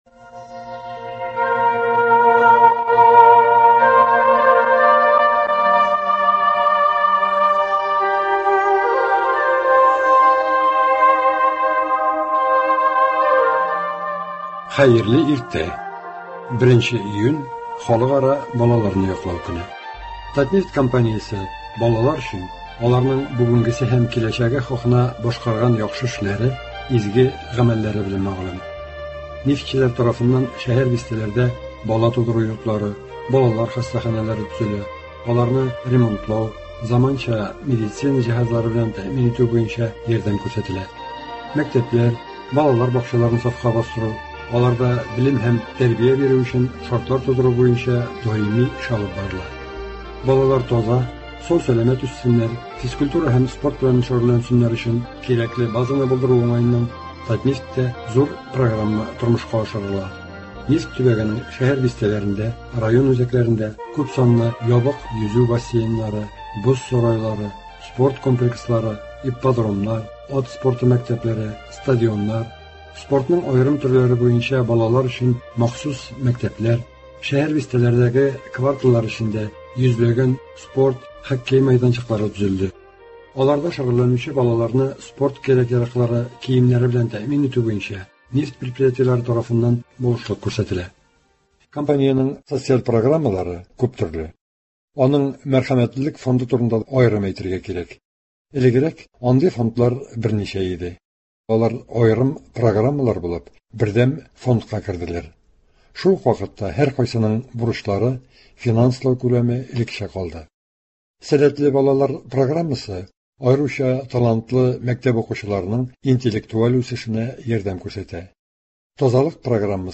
репортажларда кара алтын табучыларның хезмәт һәм ял шартлары, мәдәният һәм социаль өлкәдәге яңалыклар чагылыш таба.